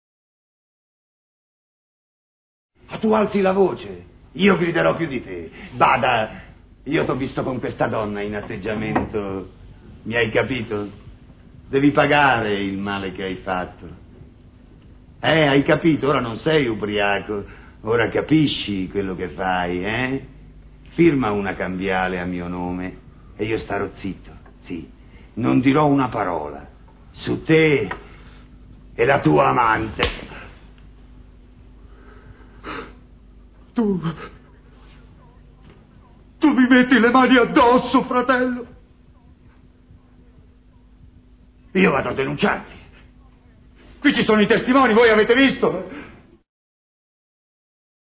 voce di Lino Troisi nel film "Oblomov", in cui interpreta Taranjef.